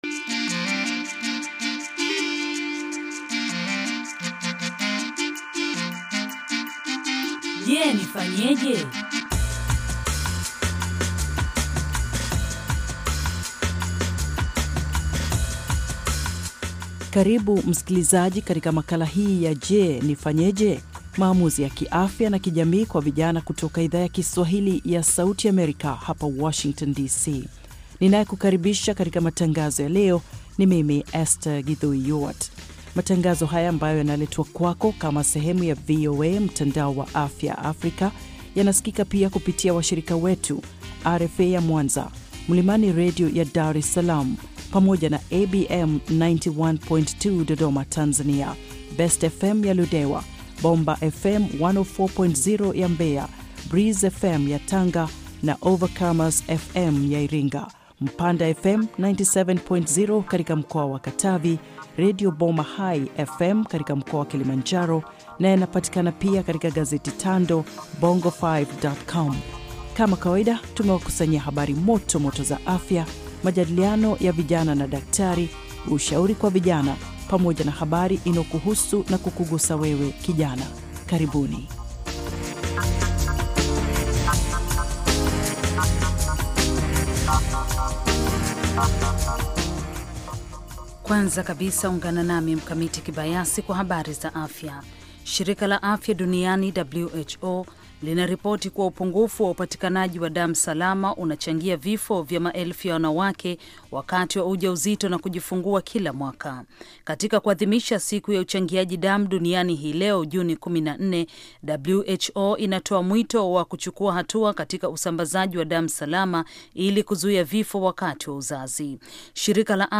Je Nifanyeje? Maamuzi ya Afya na Kijamii kwa Vijana - Kipindi cha dakika 30 kinacholenga vijana kwa kufuatulia maisha ya vijana, hasa wasichana, kuwasaidia kufanya maamuzi mazuri ya kiafya na kijamii ambayo yanaweza kuwa na maana katika maisha yao milele. Kipindi hiki kina sehemu ya habari za afya, majadiliano, na maswali na majibu kwa madaktari na wataalam wengine.